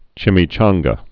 (chĭmē-chänggə)